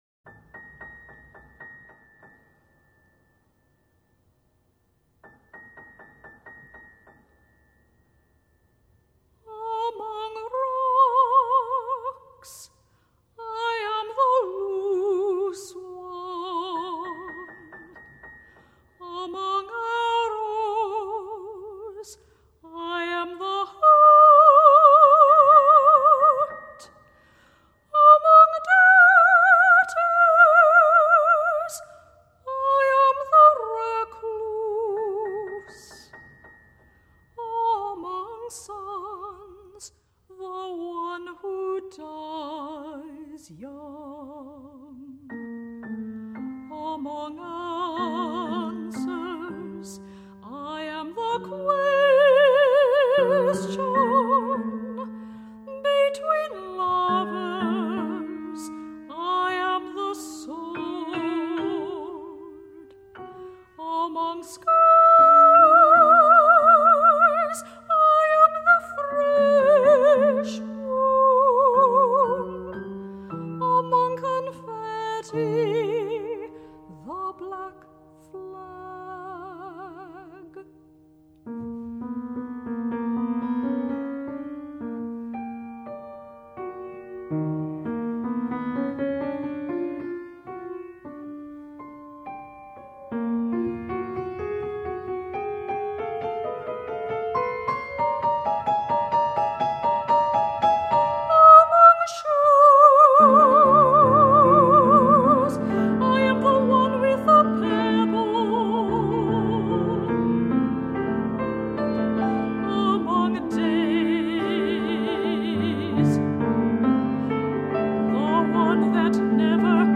for Soprano and Piano (1982)